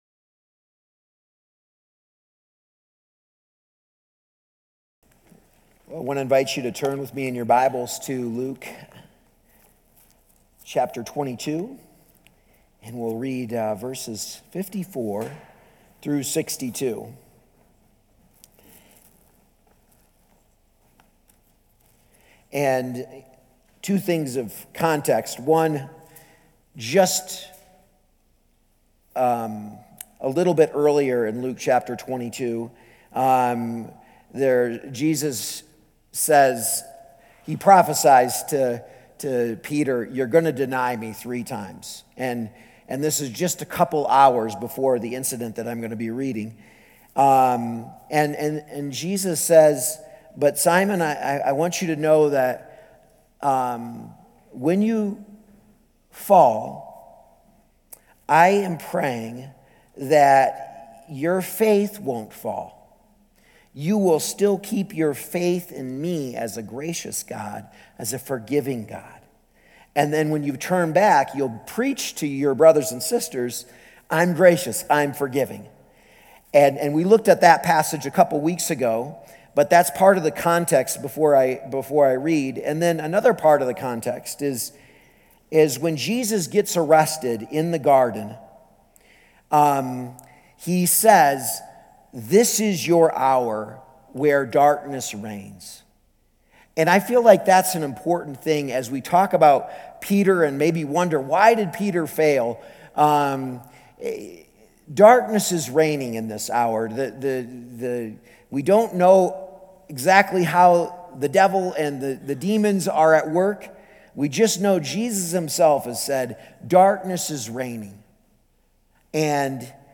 A message from the series "Encountering the Cross."